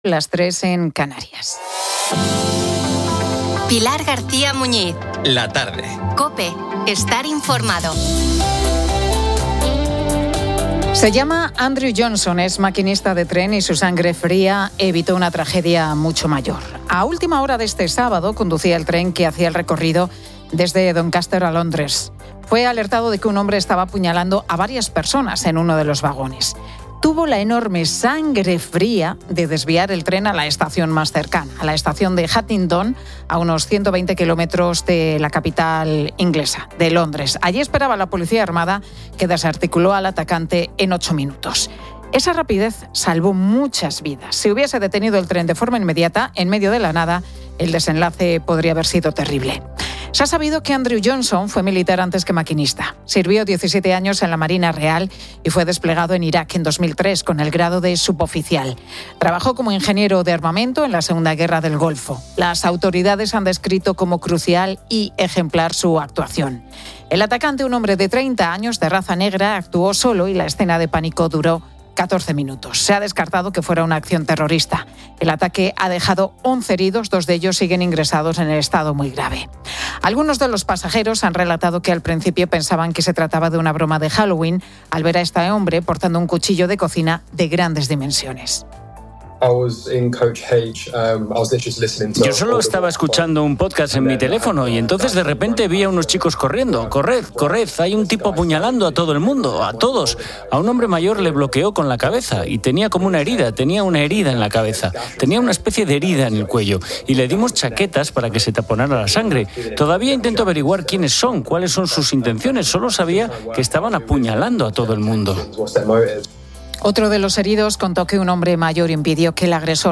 La Tarde 16:00H | 03 NOV 2025 | La Tarde Pilar García Muñiz informa de la apertura del juicio al fiscal general del Estado y de la decisión del juez Puente de llevar a juicio la trama de las mascarillas. Además, Carlos Mazón dejará la presidencia de la Generalitat Valenciana.